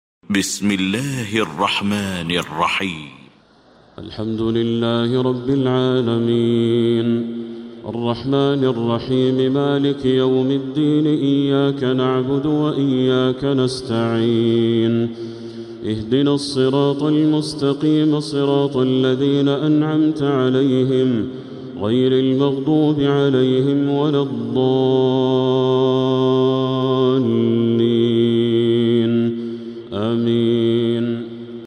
سورة الفاتحة Surat-Al-Fatiha > مصحف تراويح الحرم المكي عام 1447هـ > المصحف - تلاوات الحرمين